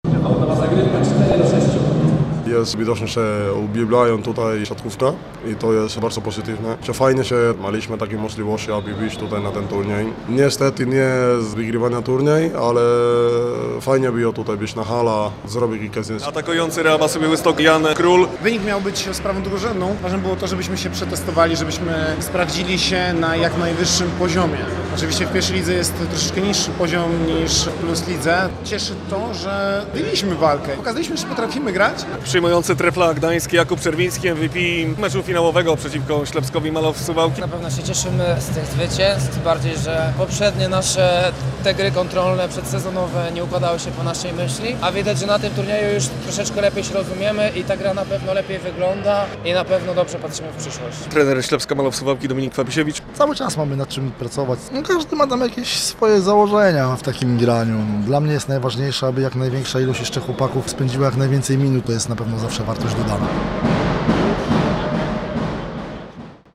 Memoriał Franciszka Ilczuka w Białymstoku - relacja